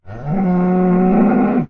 mob / cow4